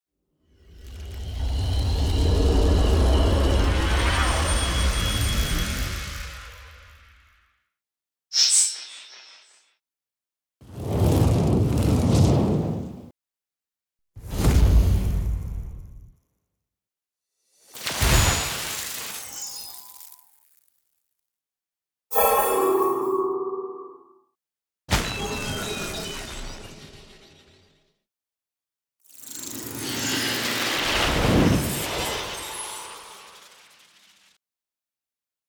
In this release you will find all sort of magical spells and sounds, including castings, impacts, summoning, and buffs.
All samples were recorded at 96kHz 24 bit with a Zoom H5 and Sennheiser ME 67.